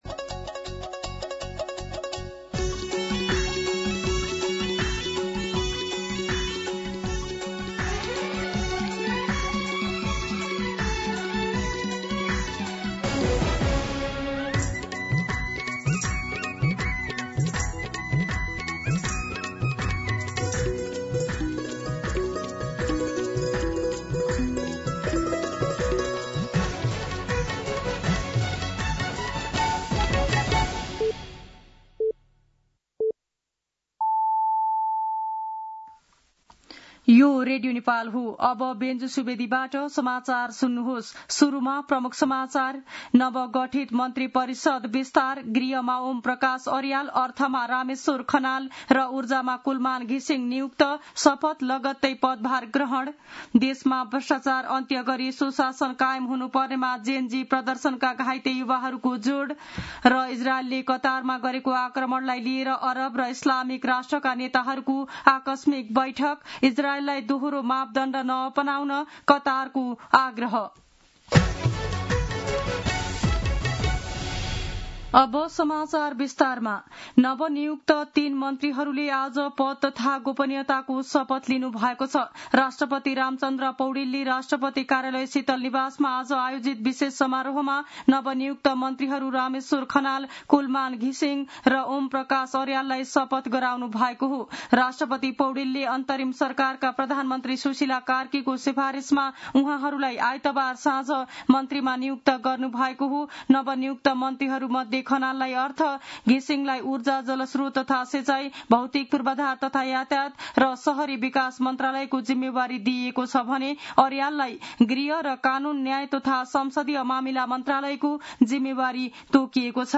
दिउँसो ३ बजेको नेपाली समाचार : ३० भदौ , २०८२